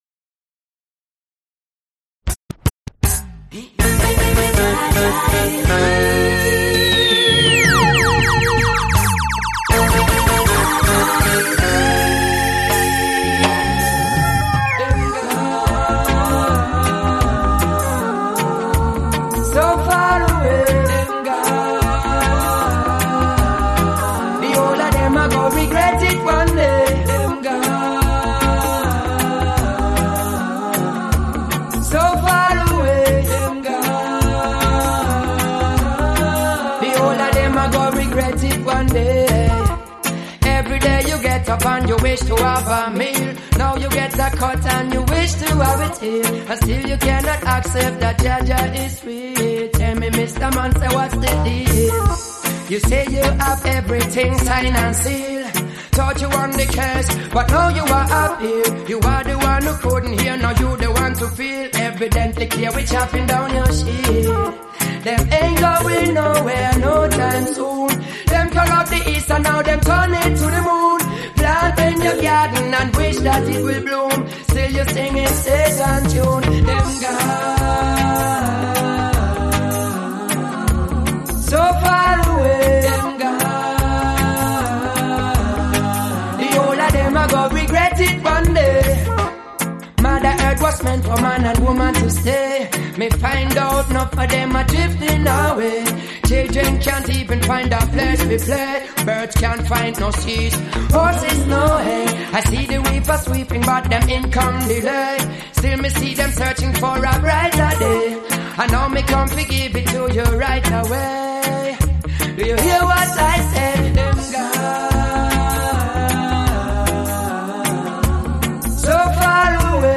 Talented Nigerian disc jockey
trending REGGAE LOVE new hit songs